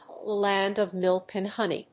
アクセントa lánd of mílk and hóney